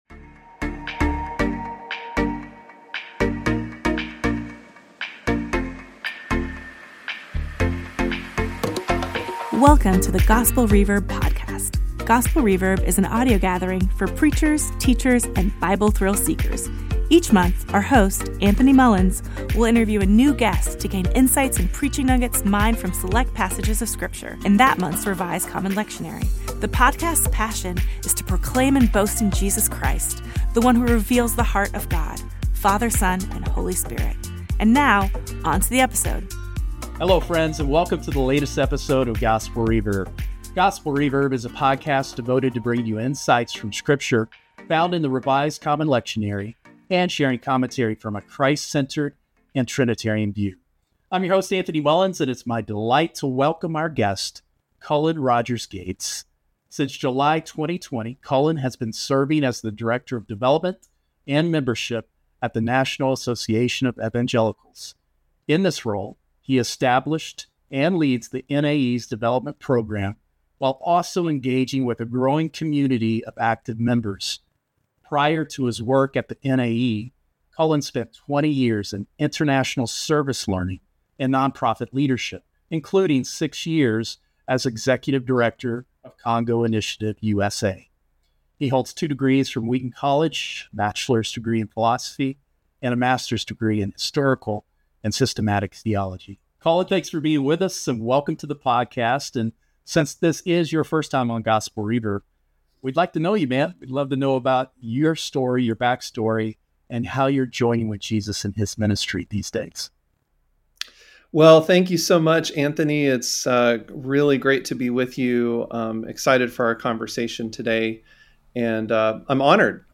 interviews outstanding preachers, theologians and church practitioners from around the globe who provide Christ-centered observations on the lectionary texts.